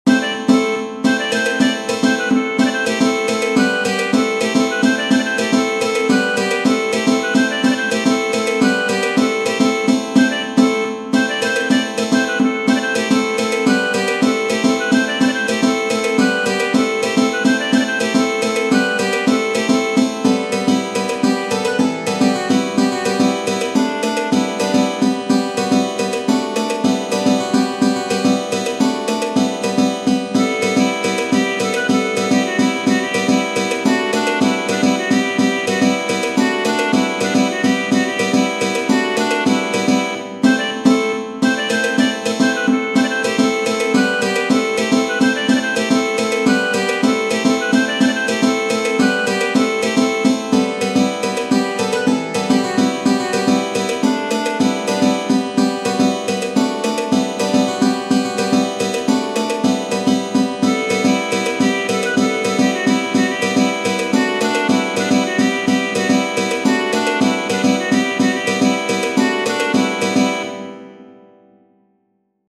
Tradizionale Genere: Folk "Jove male mome", o "Jove malaj mome", o "Yova", (in italiano: Jova, piccola ragazza) è una canzone e una danza veloce originaria della regione di Sopluk in Bulgaria e conosciuta anche in Serbia.